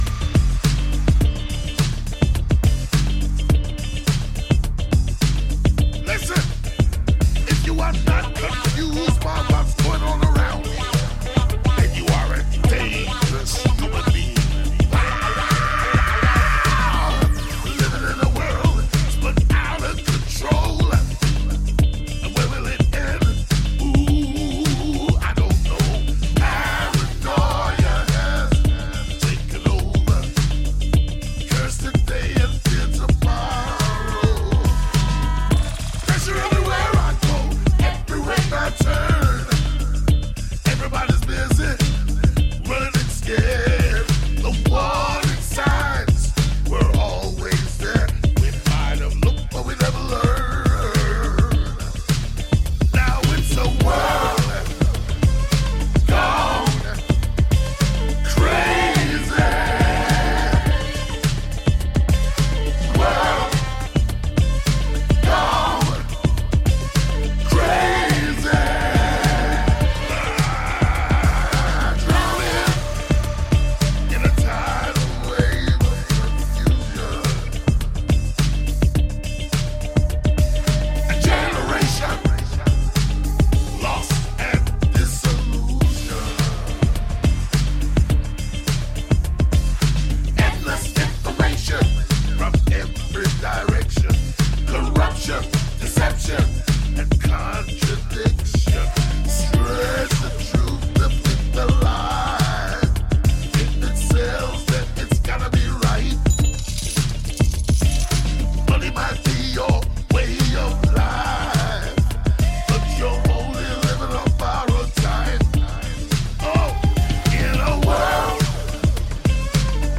dirty, bluesy singing voice
Electric rock dub that will remind you!